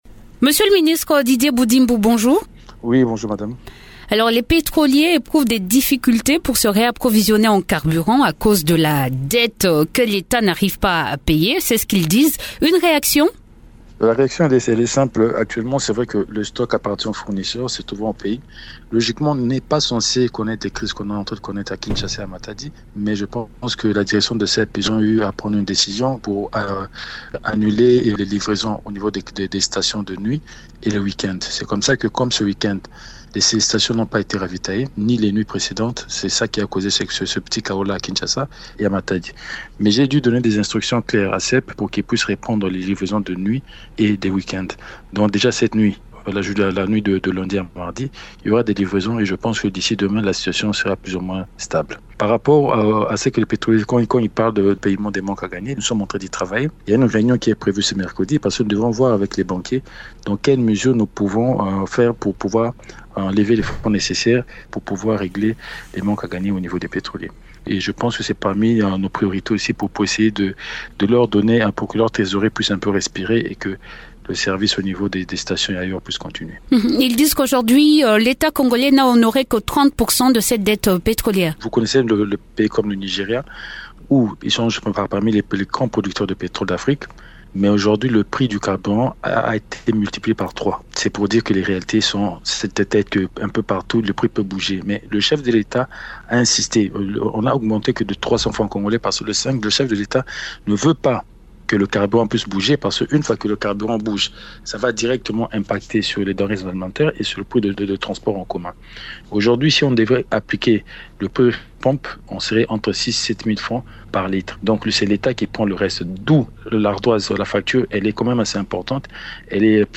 Invité de Radio Okapi, le ministre des Hydrocarbures, Didier Budimbu affirme que la difficulté actuelle d’approvisionnement en carburant est logistique.